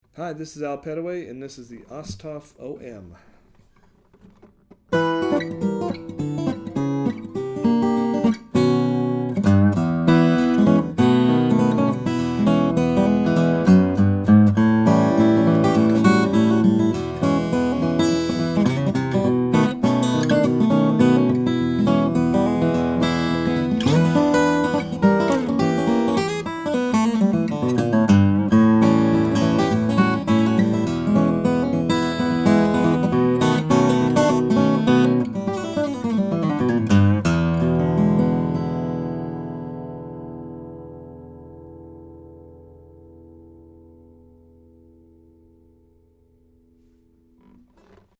2006 Osthoff OM Mahogany/Adirondack - Dream Guitars
Here we have an Osthoff OM built with a long 26″ scale for low tunings.
The body is Mahogany and Adirondack so it has a powerful voice with good headroom.…